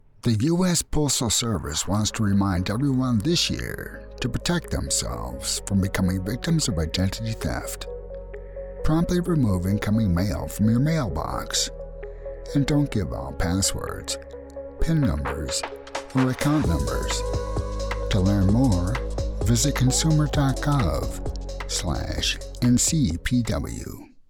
USPS Delivers- Warm, Gritty, Resonant, Genuine, Sincere, Authentic, Professionl, Spokesperson,
North American Midwest, Upper Midwest